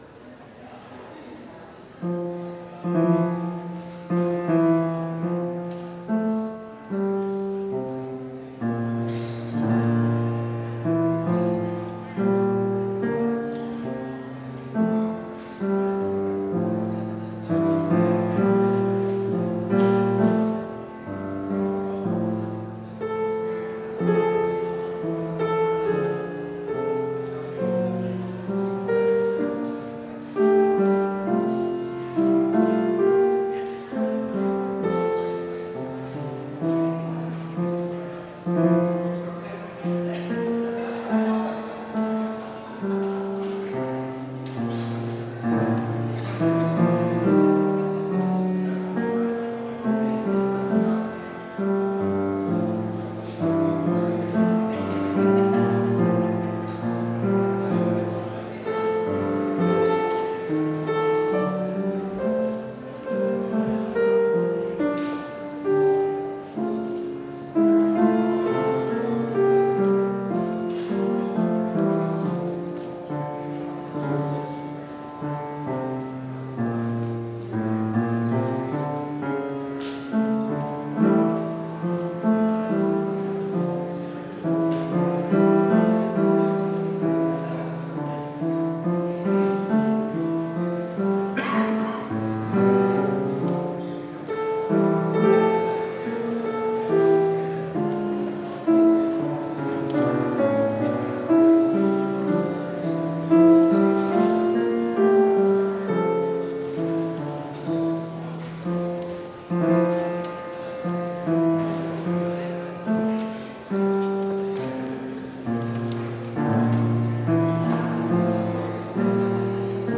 Complete service audio for Chapel - December 11, 2019